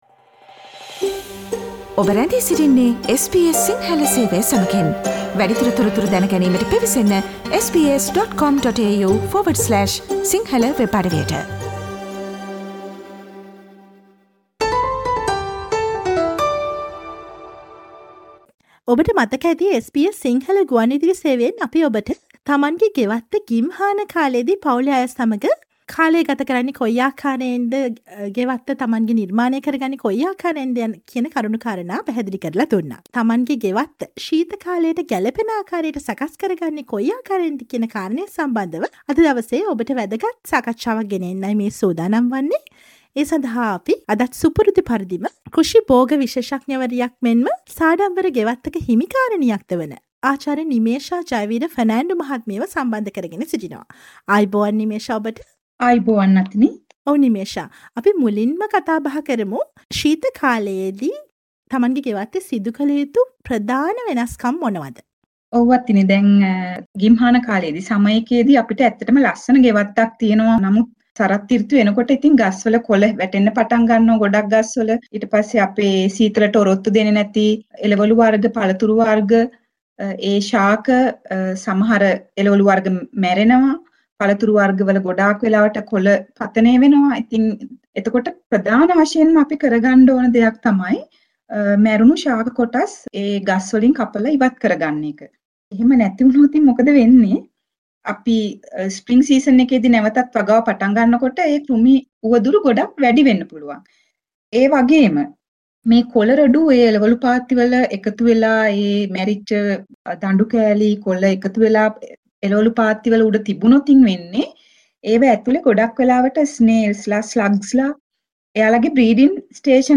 මෙල්බන් සිට SBS සිංහල ගුවන්විදුලි සේවය සමඟ සිදු කළ සාකච්ඡාව